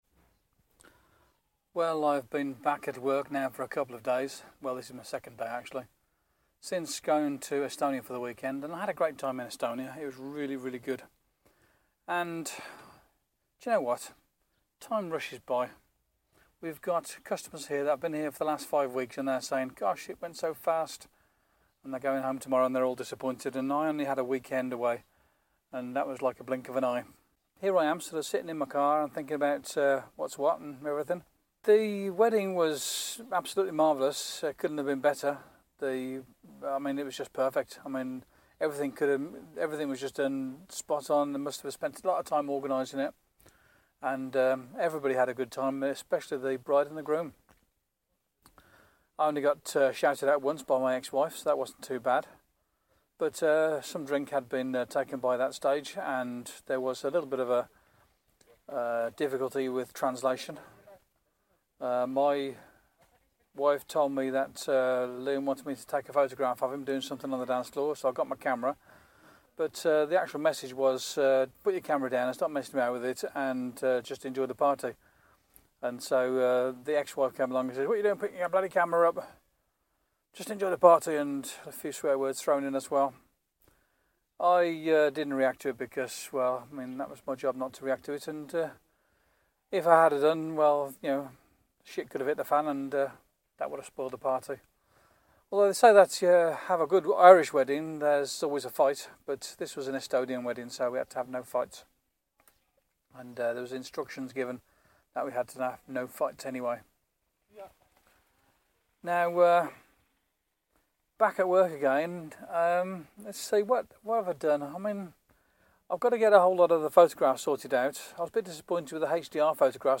I was pleased that I have found a good workflow for making audio boos on the Galaxy S3.